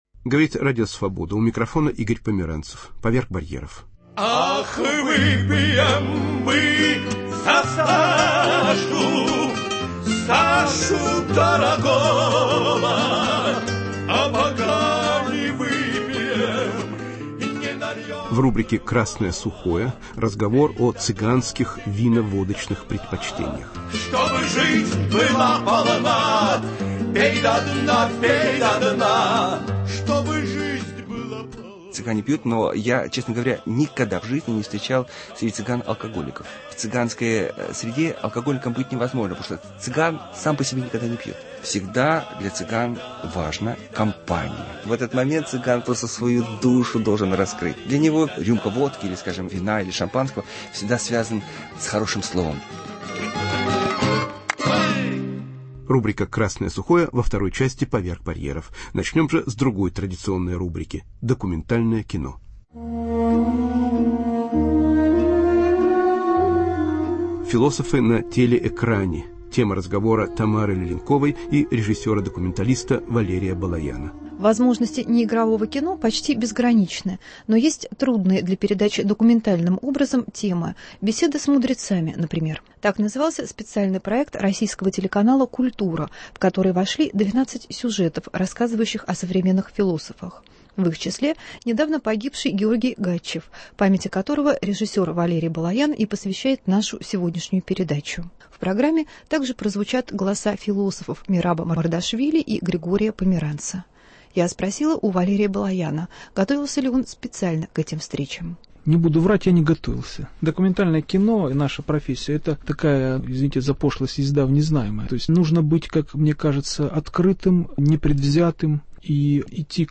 Философы на телеэкране: звуковые фрагменты из фильмов о М.Мамардашвили, Г.Гачеве и Г.Померанце